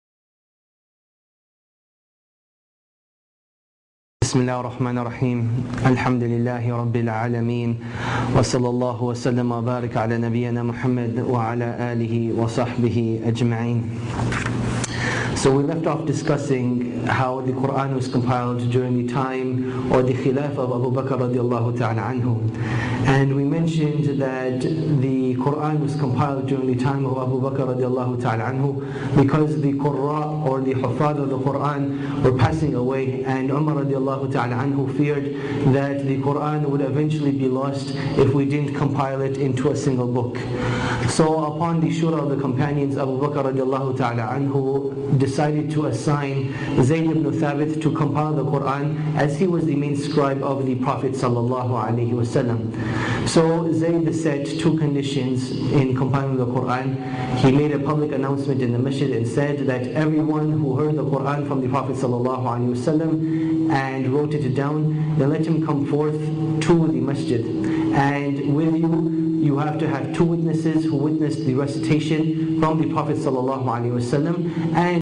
This is part of a series of lectures on the sciences of the Qur’aan delivered at the QSS centre in the summer of 2007.